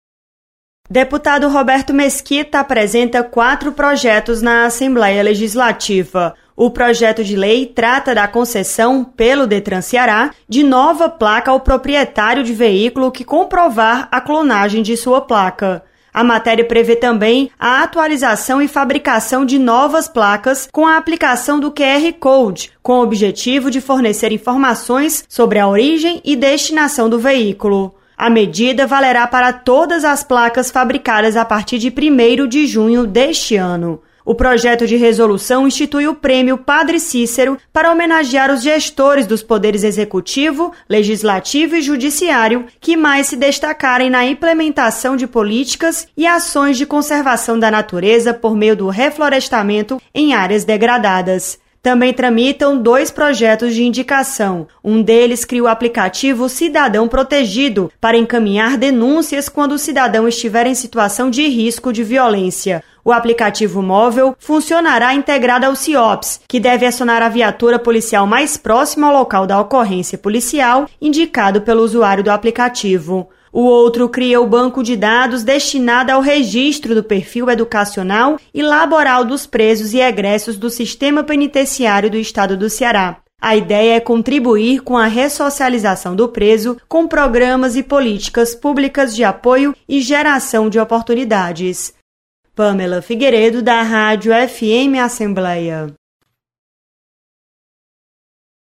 Projeto prevê restituição de placas clonadas. Repórter